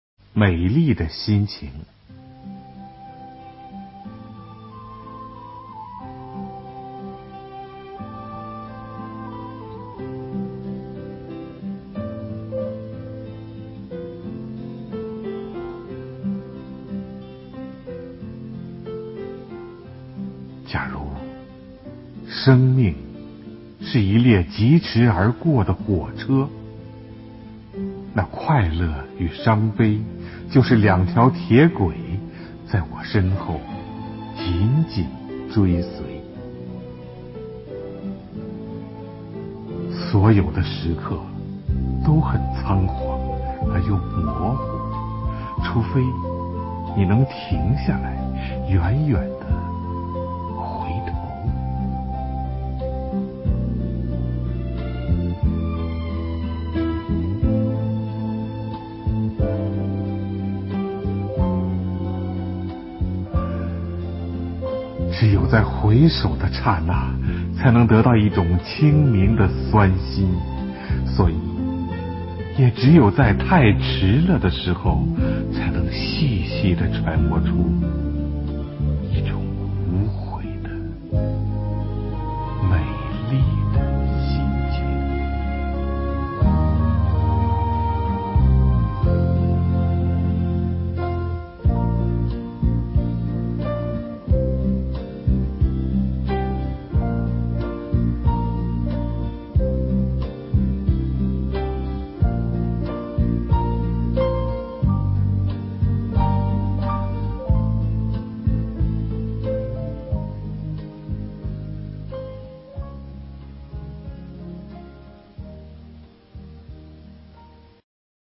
首页 视听 经典朗诵欣赏 席慕容：委婉、含蓄、文雅